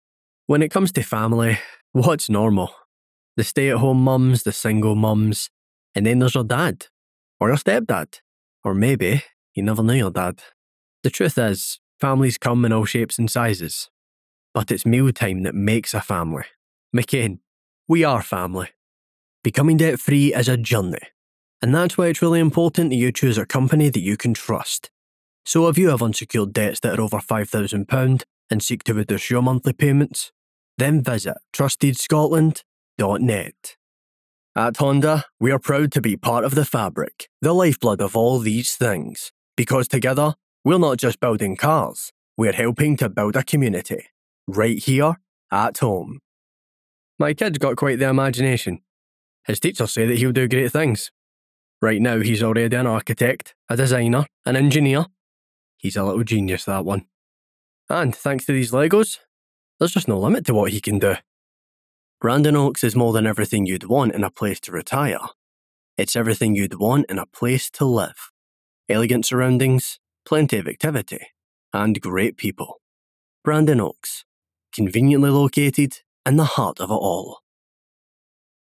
0706Demo-reel_Commercial.mp3